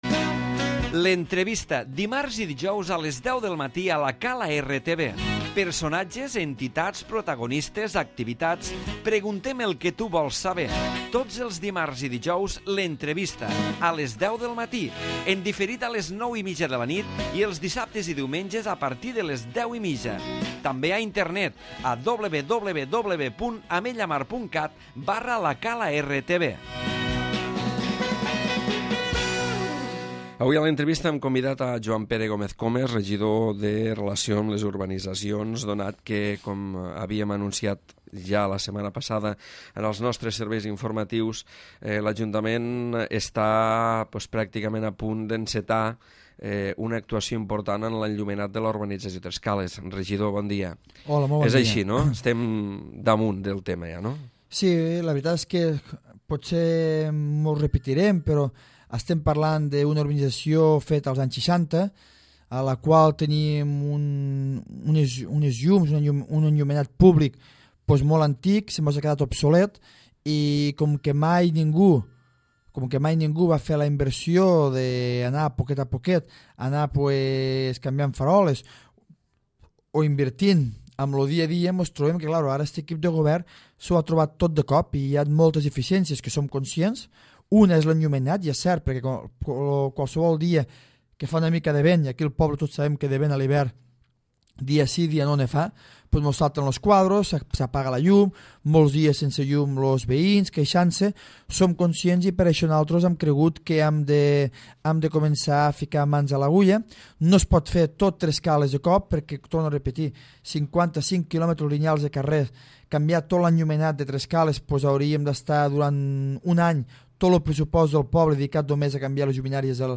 L'Entrevista
Joan Pere Gómez Comes, regidor de Relacions amb les Urbanitzacions, ha parlat a l'entrevista del projecte de millora d'una part de l'enllumenat públic de Tres Cales.